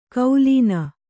♪ kaulīna